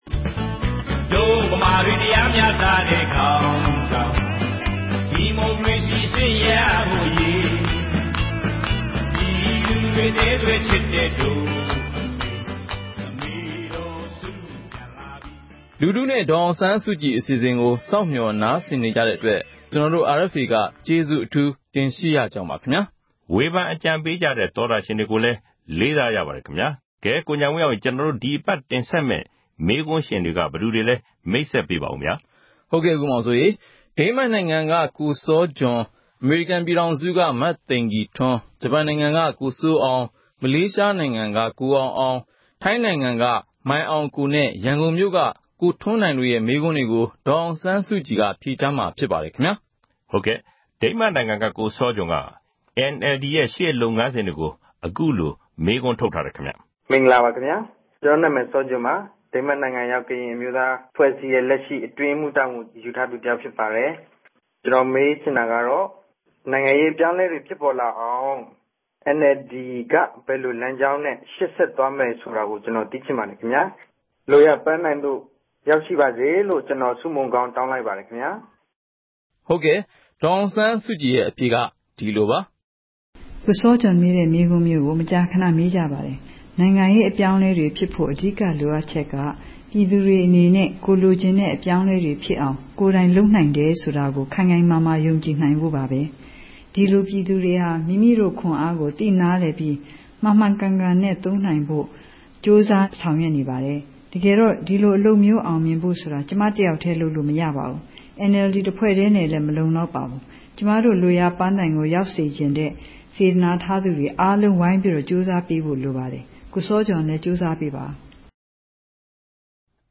‘လူထုနှင့် ဒေါ်အောင်ဆန်းစုကြည်’ အပတ်စဉ်အမေးအဖြေ